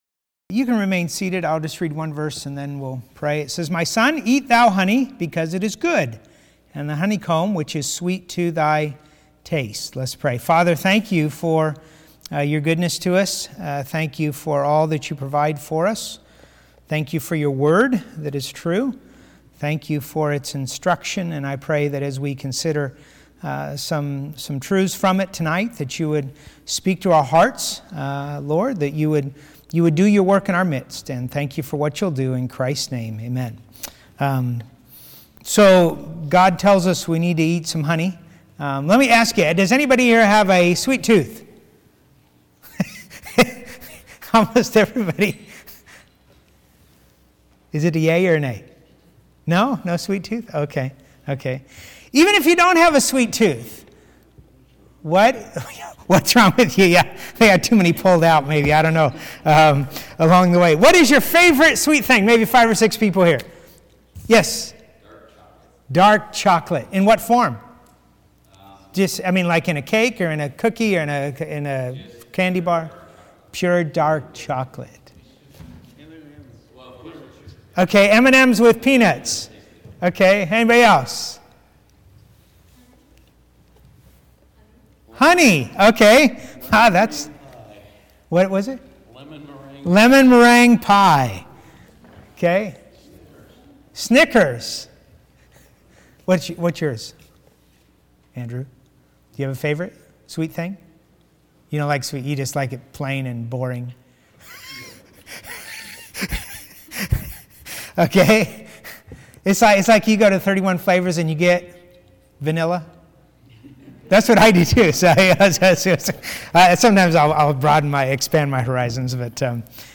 Wednesday Prayer Meeting